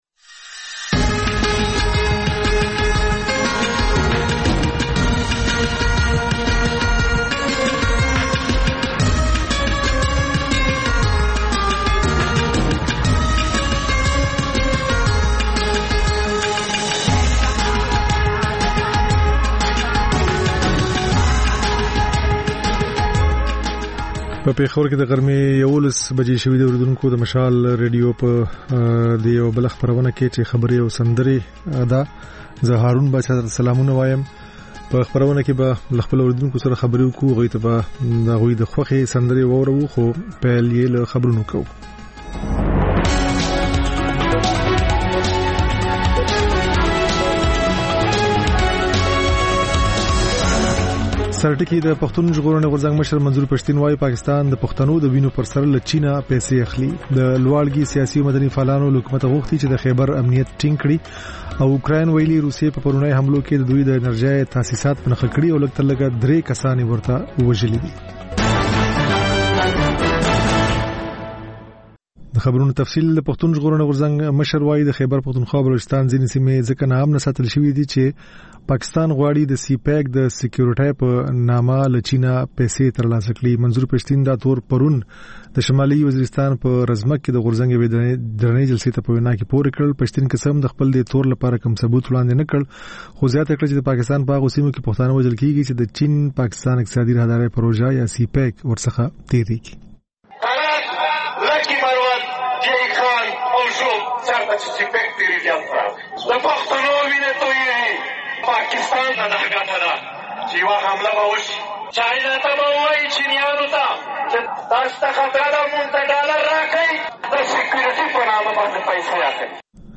په دې خپرونه کې تر خبرونو وروسته له اورېدونکو سره په ژوندۍ بڼه خبرې کېږي، د هغوی پیغامونه خپرېږي او د هغوی د سندرو فرمایشونه پوره کول کېږي.